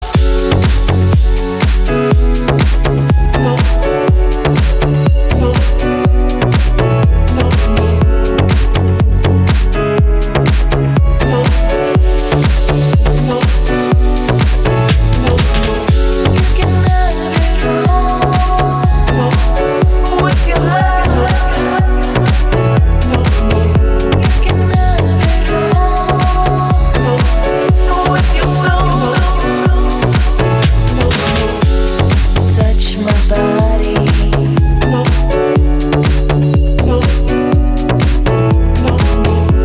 • Качество: 320, Stereo
женский вокал
deep house
релакс
чувственные